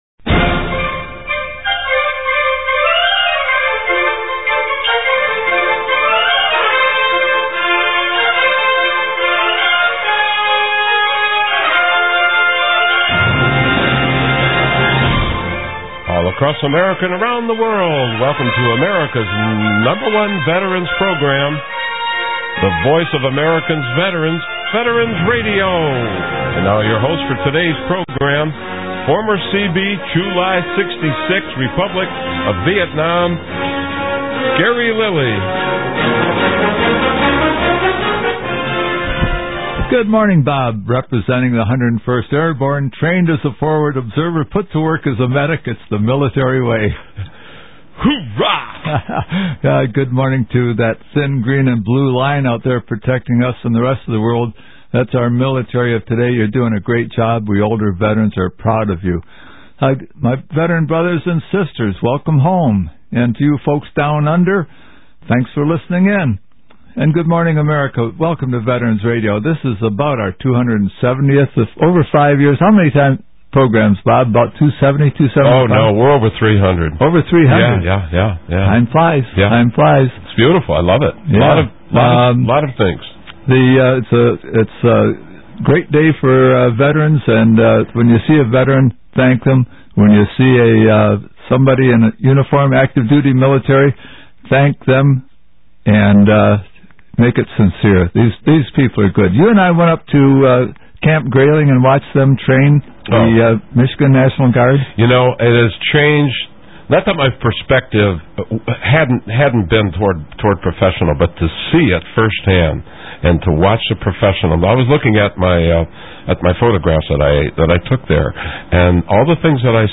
is joined by Medal of Honor recipient Sammy L. Davis, who recounts his extraordinary heroism in Vietnam.